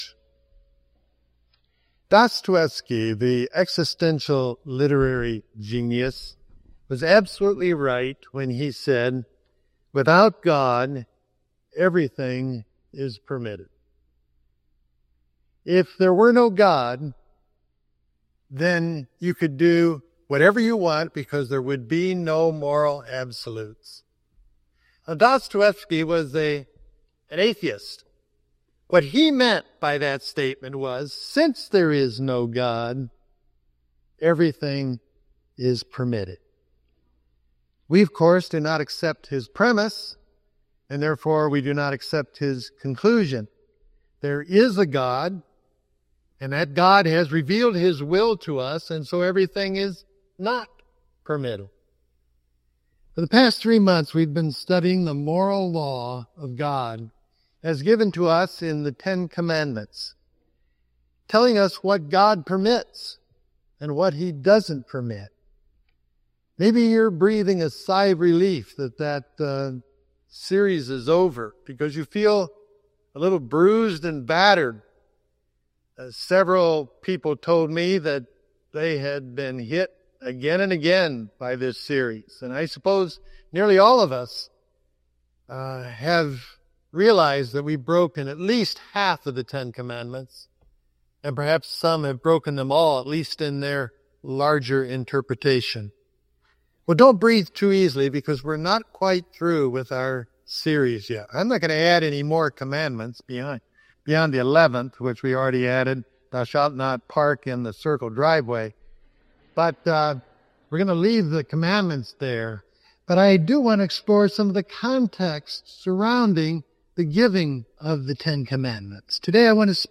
I suspect the average person in this congregation has been nailed by at least half of the commandments, and some have confessed to me that they have broken all ten, at least in their broader interpretation.